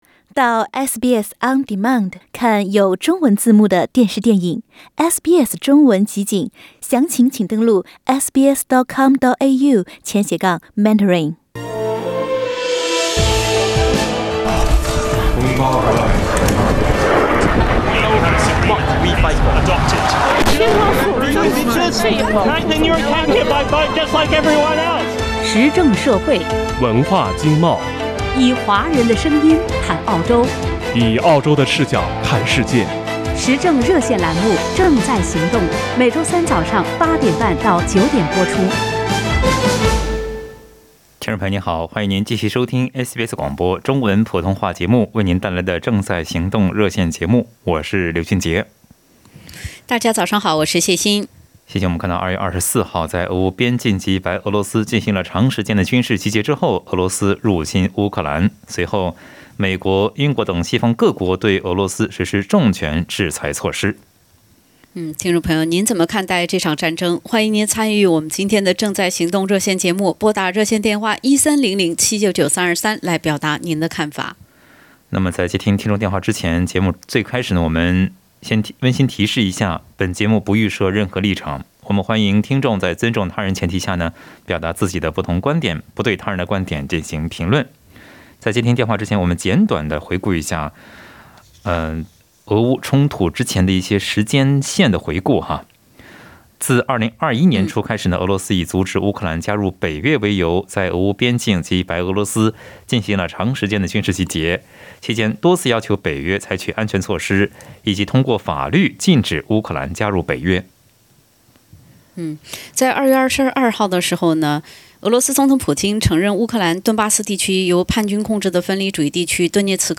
在本期《正在行动》热线节目中，听友们就俄罗斯入侵乌克兰这场战争表达了看法。